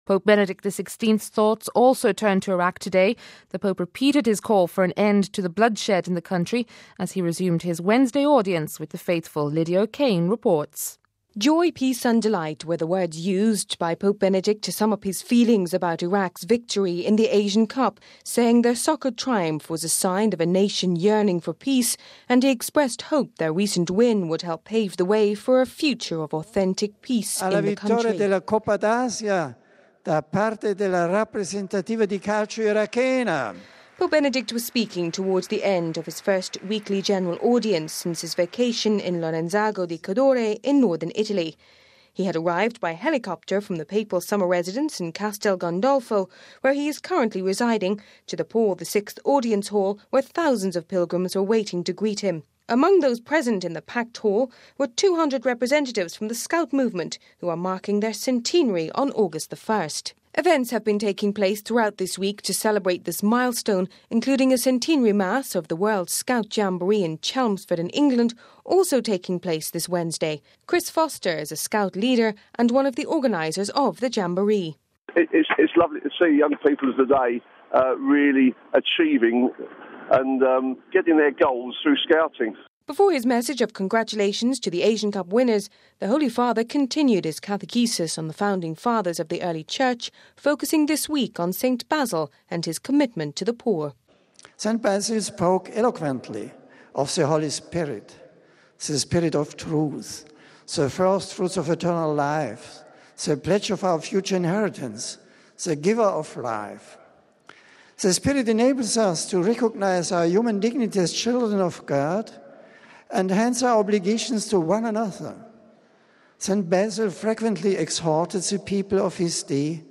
(1 Aug 07 - RV ) Pope Benedict XVI resumed his weekly appointment with the faithful this Wednesday, after his three week holiday in Northern Italy. During the audience the Pope carried on from where he had left off - tracing the lives and works of the early fathers of the Church. His thoughts also turned to Iraq and to the thousands of young scouts and guides world wide who were celebrating the 100th anniversary of the movement's foundation this Wednesday.